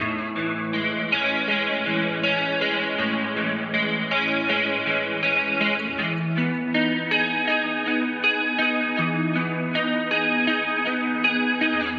Index of /DESN275/loops/Loop Set - Guitar Hypnosis - Dream Pop Guitar
Love_80_A_GuitarArp.wav